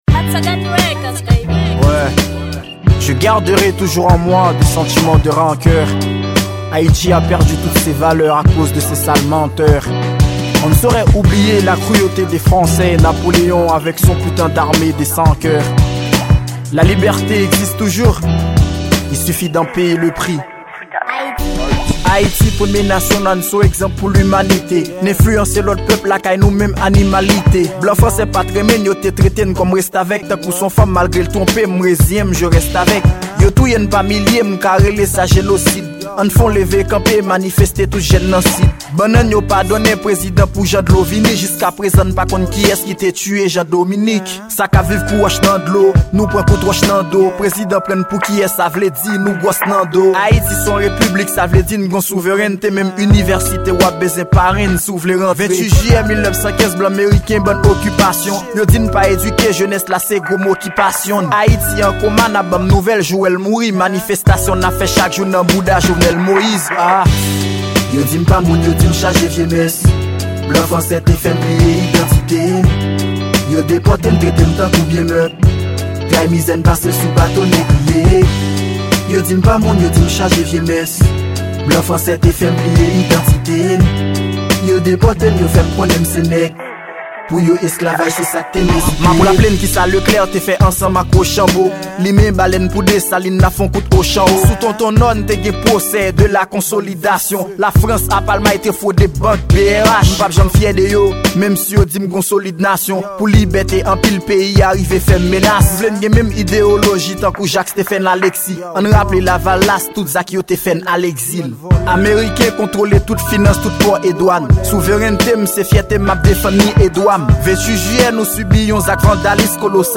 Genre: Rap concsient.